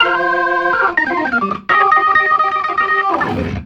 RIFF 2    -L.wav